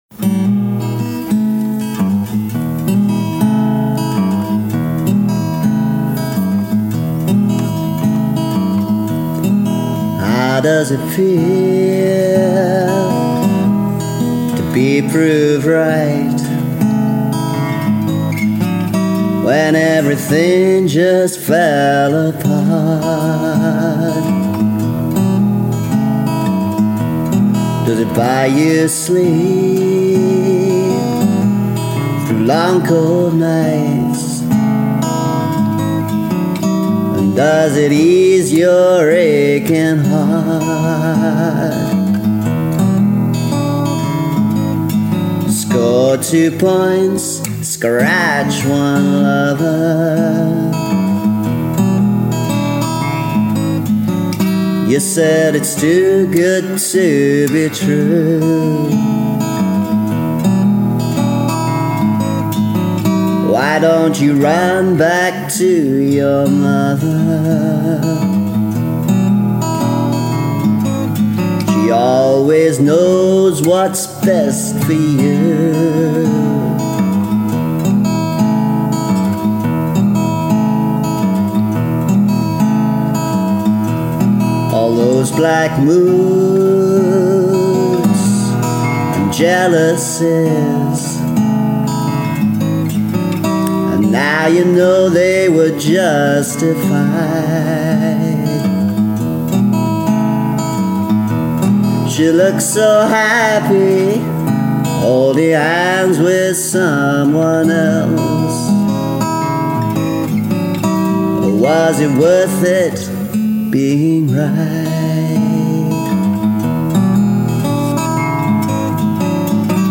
1980s studio version (2nd guitar